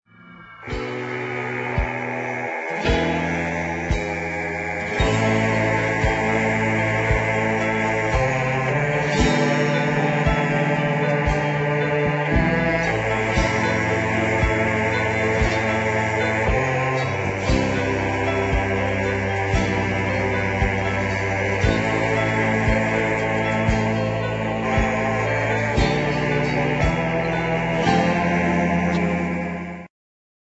thrilling medium voc.